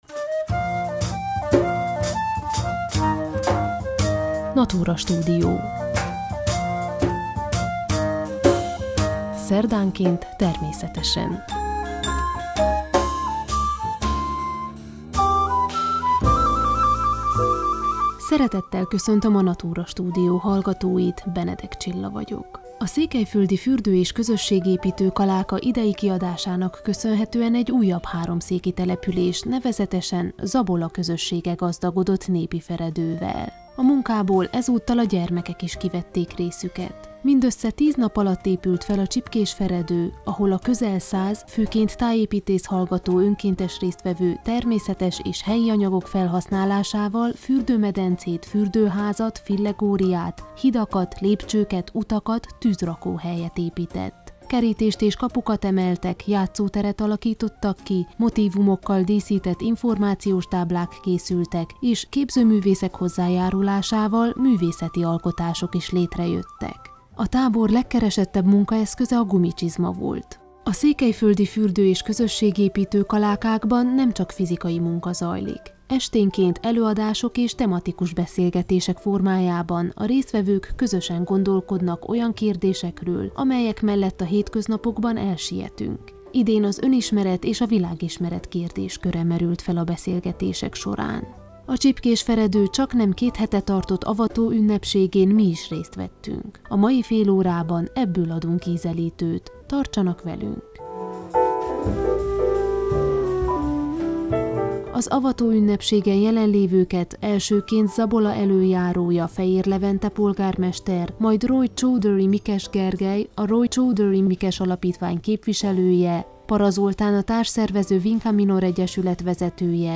A Csipkés Feredő avatóünnepségén mi is részt vettünk, mai adásunkban ebből adunk ízelítőt.